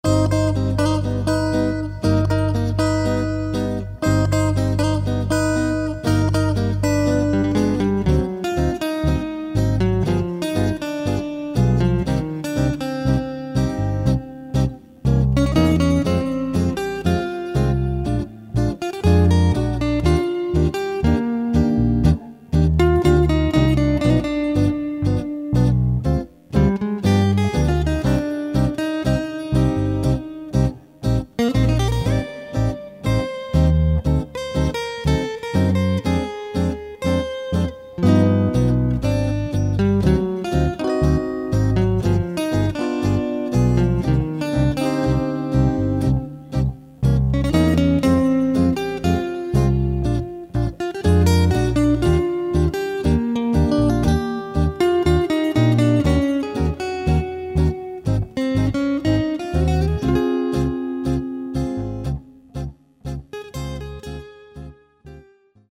Tango per chitarra ed orchestra.